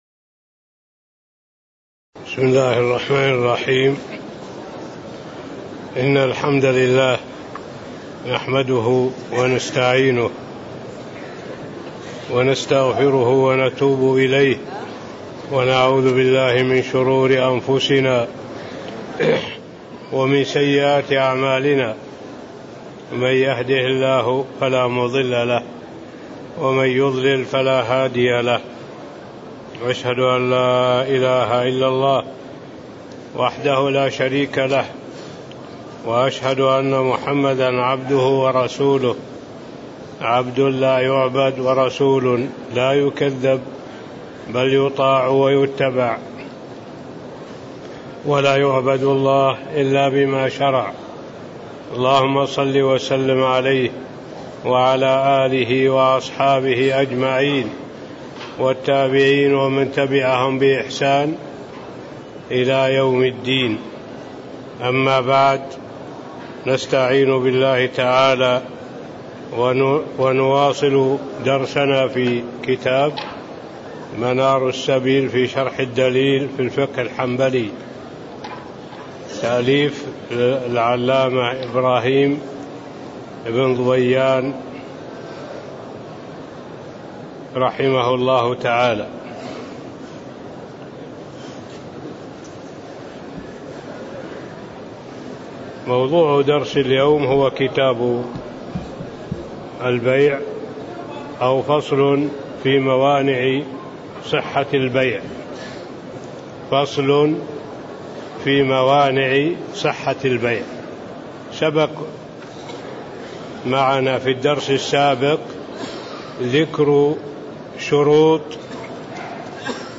تاريخ النشر ٤ محرم ١٤٣٧ هـ المكان: المسجد النبوي الشيخ